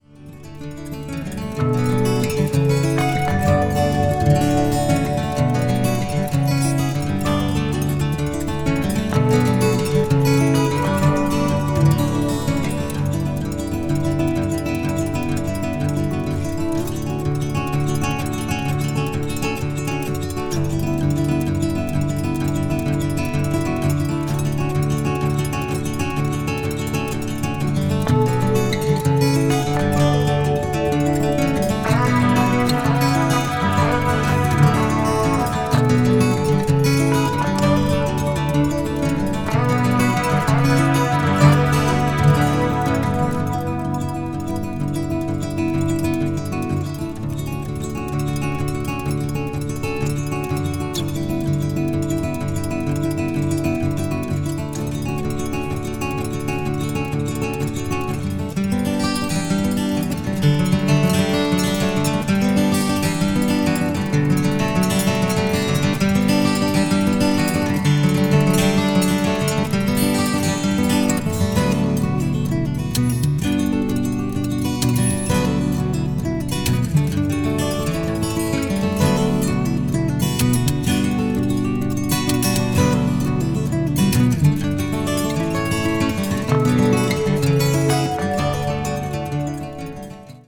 media : EX/EX(some slightly noises.)
contemporary jazz   guitar duo   new age